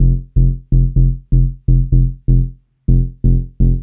cch_bass_loop_pluck_125_Fm.wav